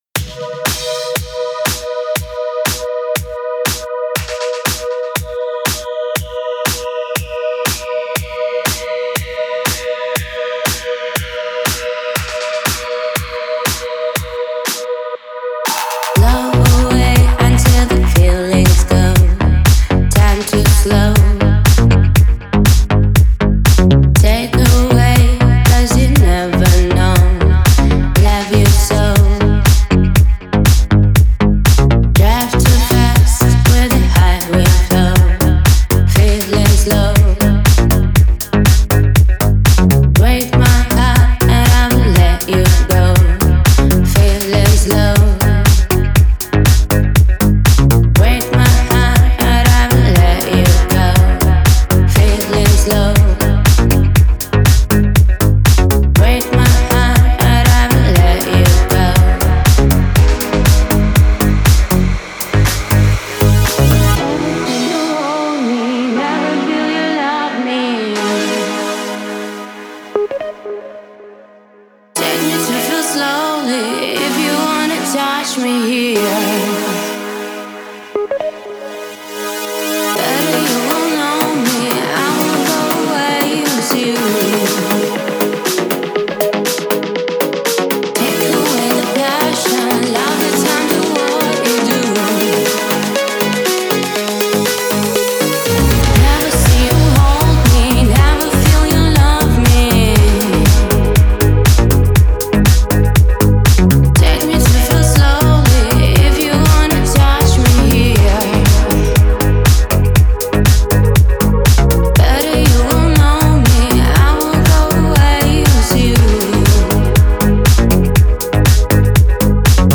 Звучание выделяется яркими синтезаторами и глубокими басами